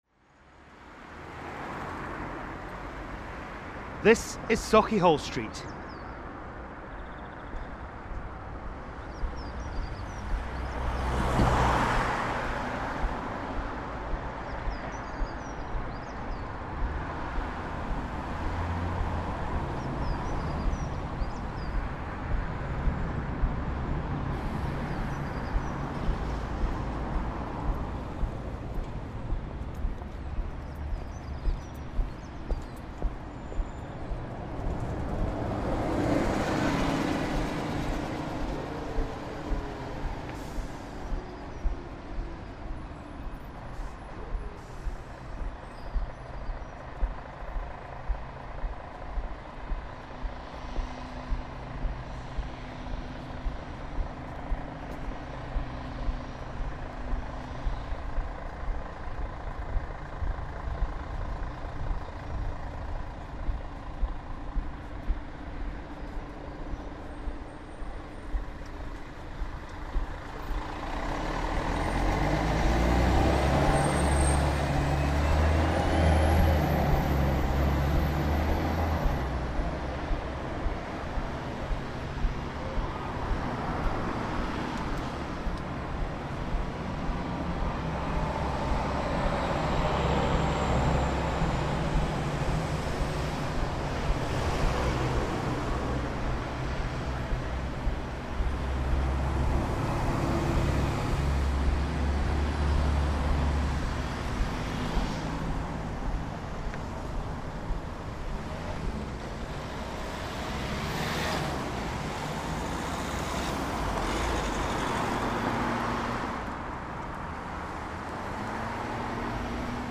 Street Sounds of Scotland: Sauchiehall St
sauchiehall-street.mp3